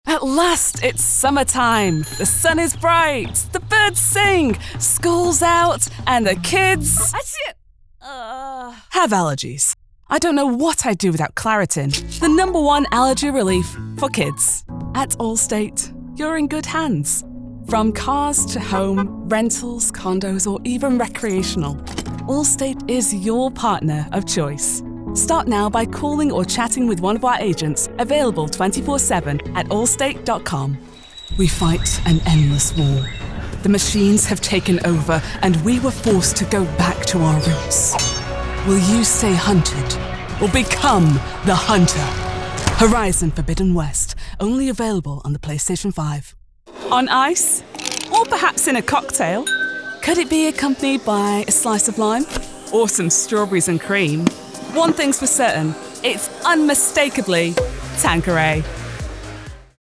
Confiable
Autoritario
Conversacional